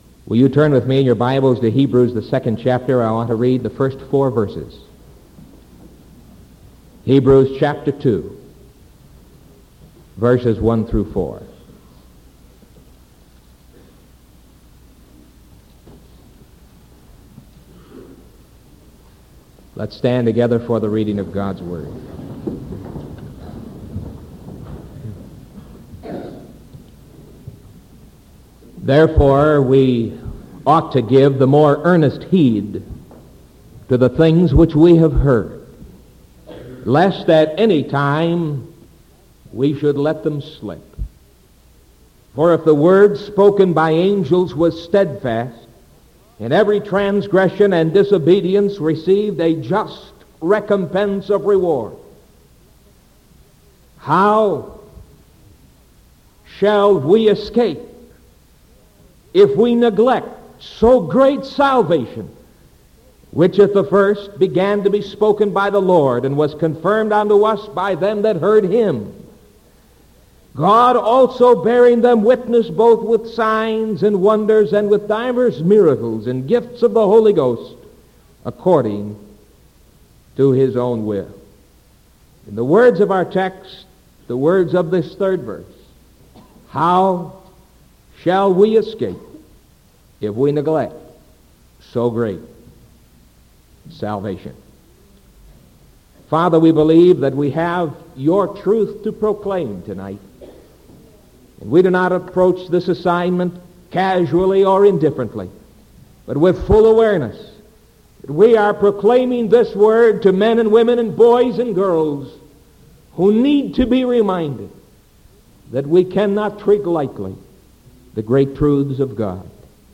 Sermon February 17th 1974 PM